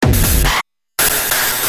Tag: 140 bpm Glitch Loops Drum Loops 295.55 KB wav Key : Unknown